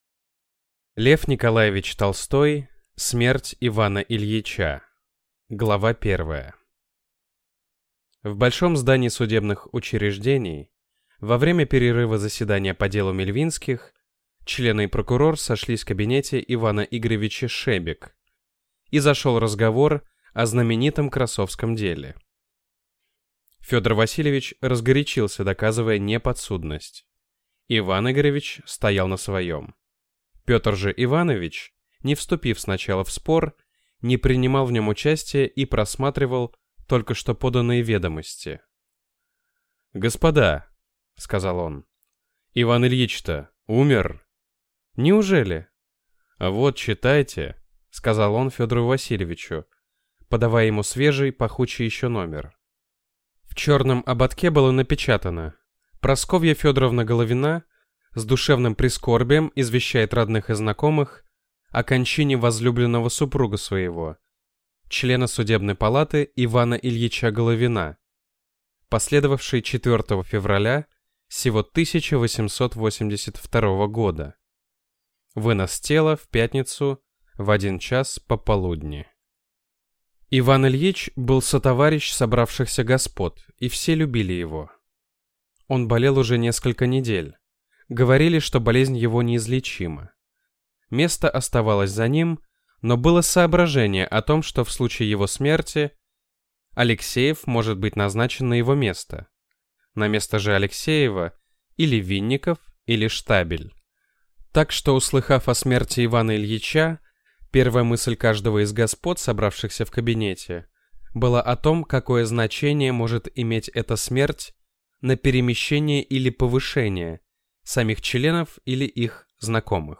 Аудиокнига Смерть Ивана Ильича | Библиотека аудиокниг